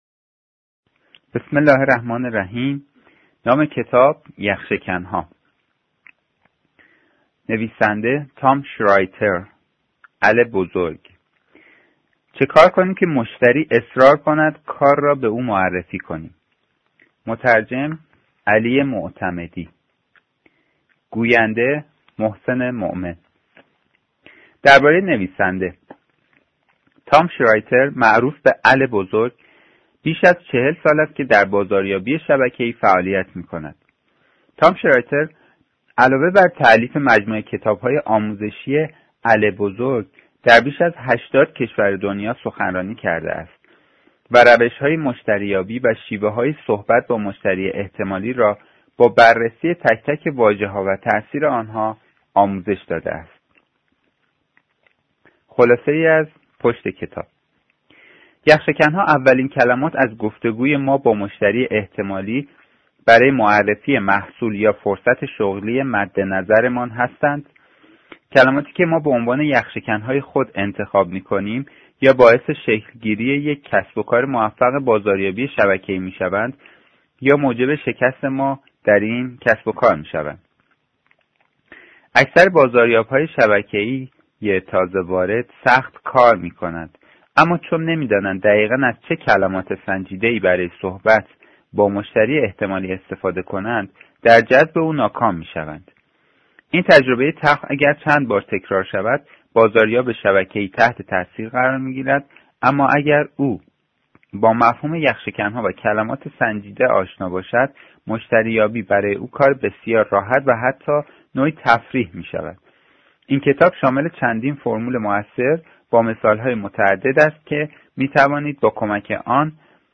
کتاب صوتی یخ شکن ها | آوا کالا | نوشته و اثر تام شرایتر بیگ ال